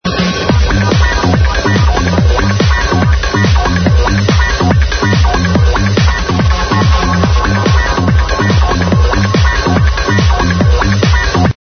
Exclamation MASSIVE Techno Tune Needs ID!!!!!
yeah would love to know this to. sounds nice